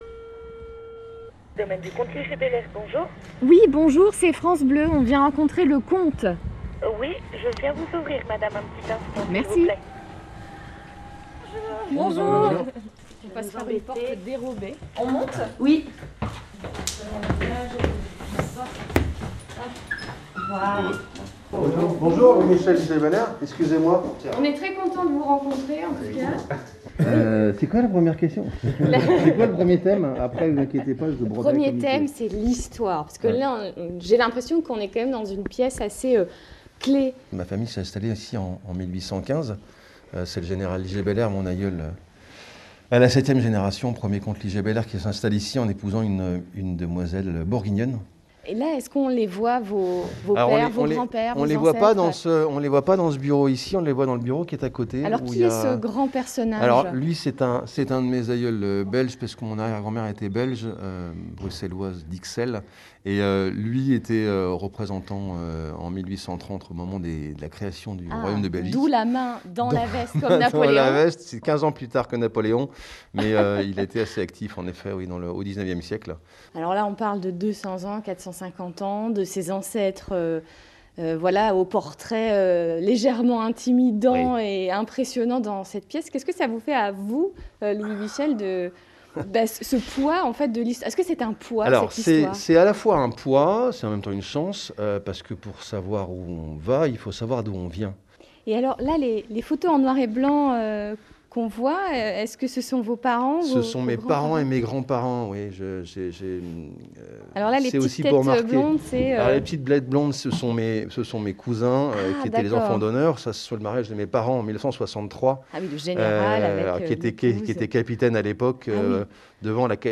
Vendredi 25 février 2022, nous accueillions France Bleu Bourgogne au Domaine : un reportage à écouter pour découvrir notre nouveau projet…